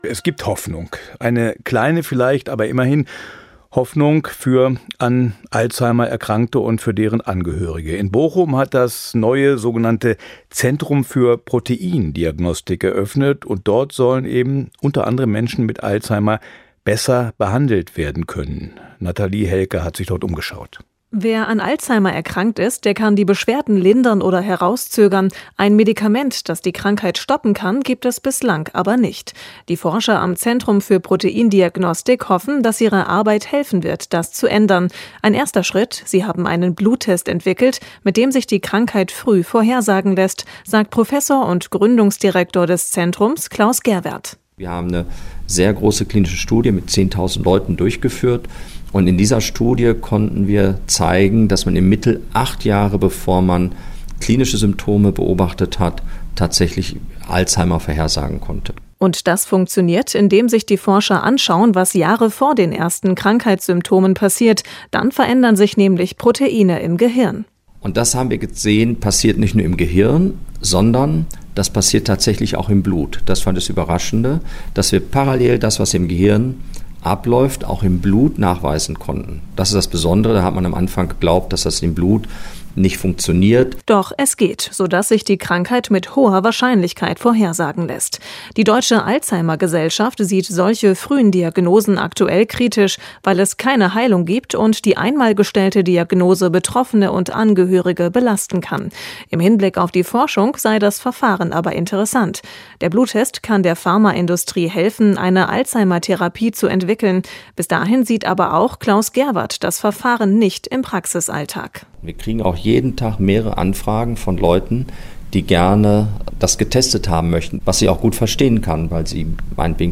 Radio-Interview in WDR 5 - Westblick, das Landesmagazin